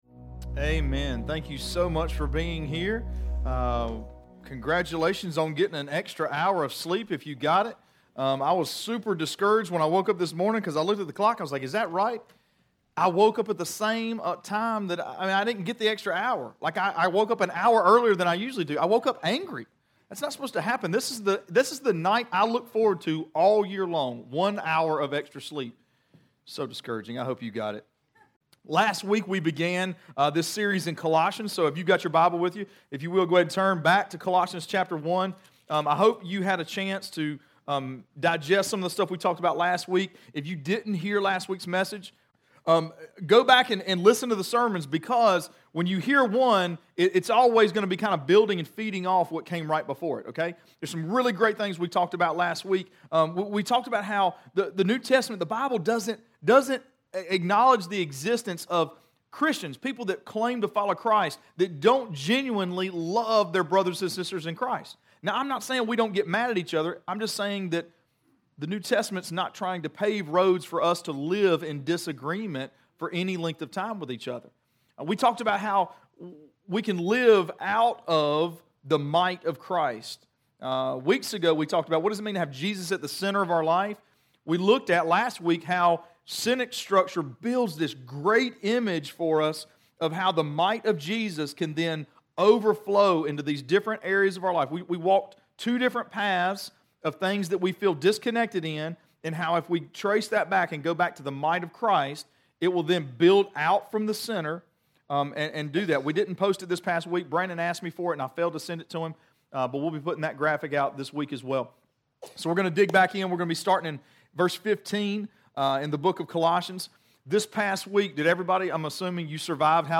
Sermons Archive - Page 32 of 60 - REEDY FORK COMMUNITY CHURCH